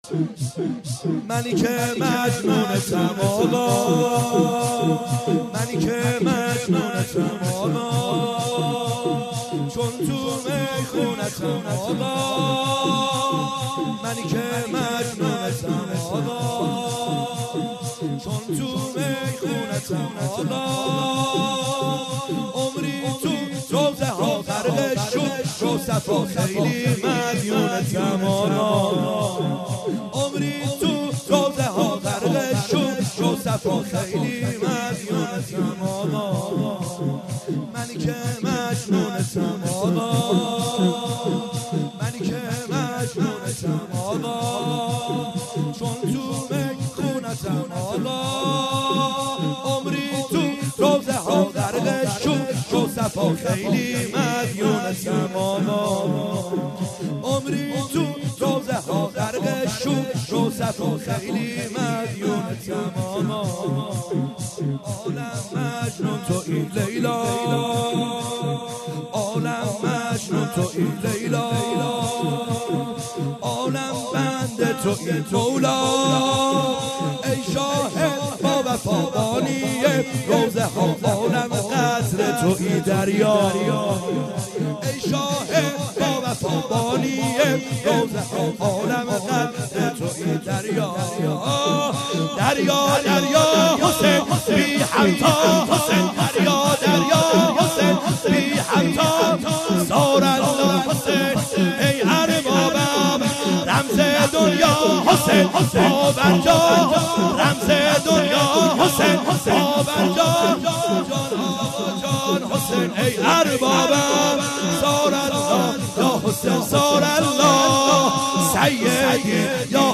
خیمه گاه - بیرق معظم محبین حضرت صاحب الزمان(عج) - شور | منیکه مجنونتم اقا